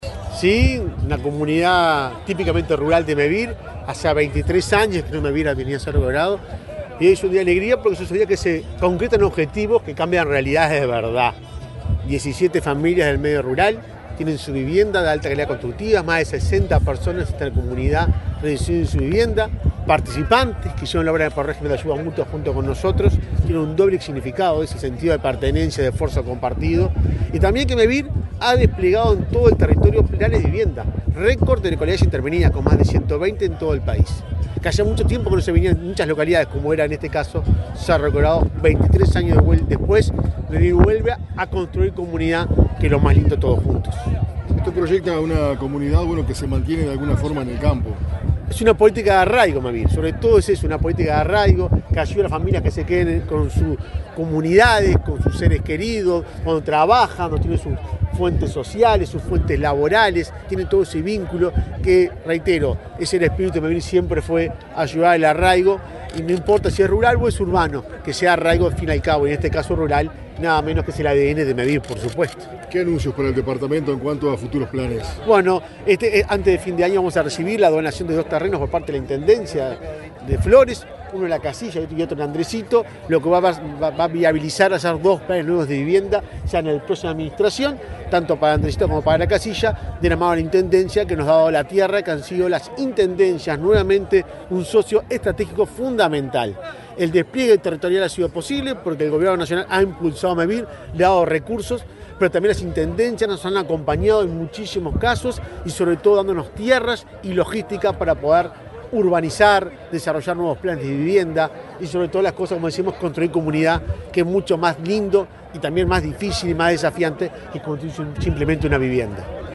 Declaraciones del presidente de Mevir, Juan Pablo Delgado
El presidente de Mevir, Juan Pablo Delgado, dialogó con la prensa, luego de participar en la inauguración de 17 viviendas nucleadas en Cerro Colorado,